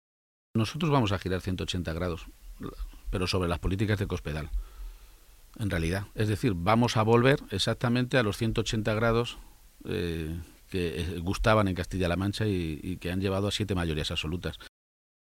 Cortes de audio de la rueda de prensa
Audio Page-entrevista Onda Cero 2